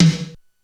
Index of /90_sSampleCDs/300 Drum Machines/Korg DSS-1/Drums02/01
HiTom.wav